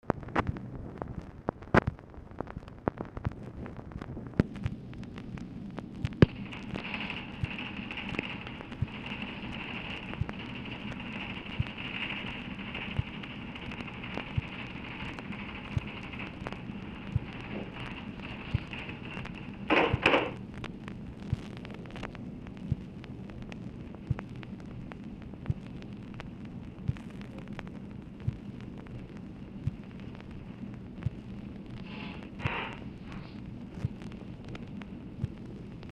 OFFICE NOISE
SOUND OF TYPEWRITER OR TELETYPE
Format Dictation belt
Oval Office or unknown location